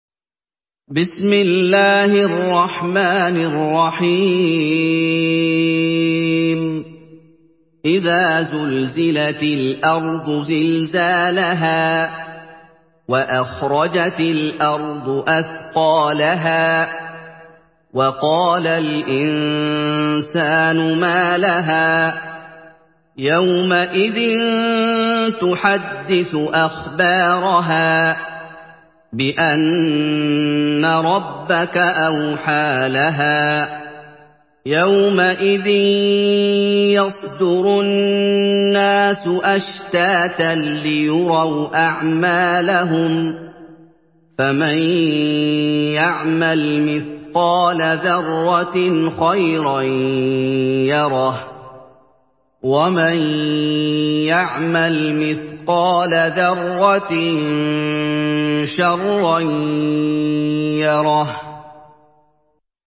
Surah Zelzele MP3 by Muhammad Ayoub in Hafs An Asim narration.
Murattal Hafs An Asim